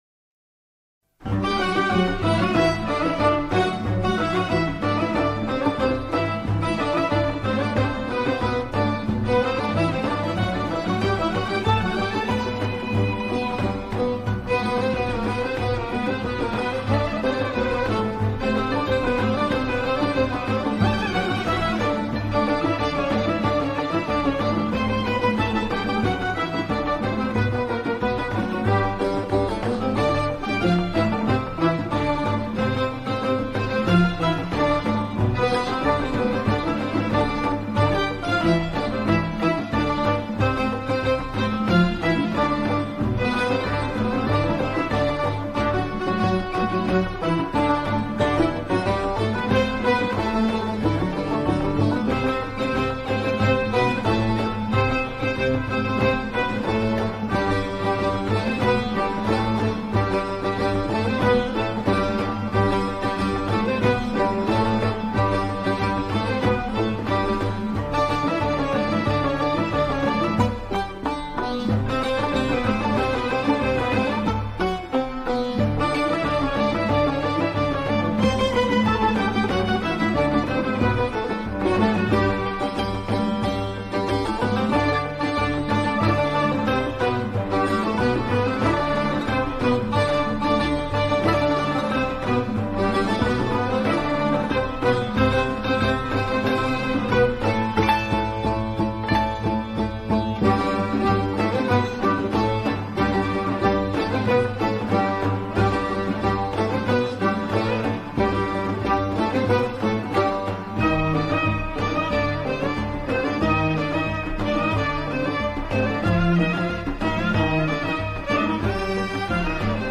مناجات
بی‌کلام